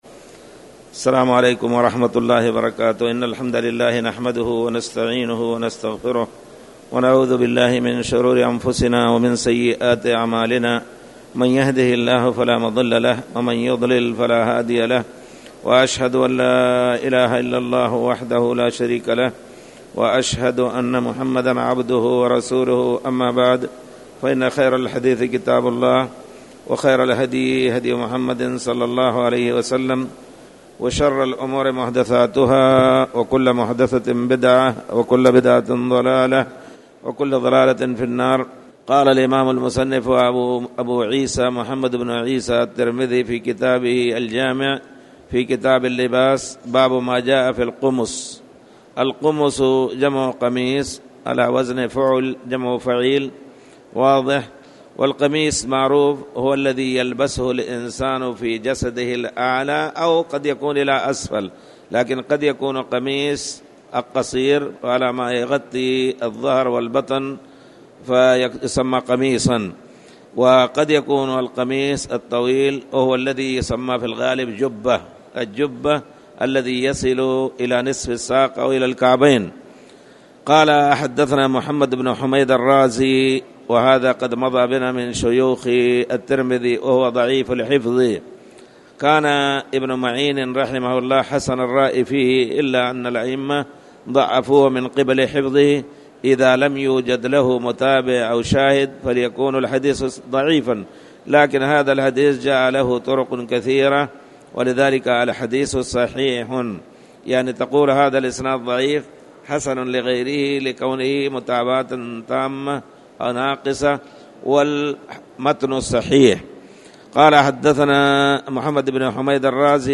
تاريخ النشر ٥ رمضان ١٤٣٨ هـ المكان: المسجد الحرام الشيخ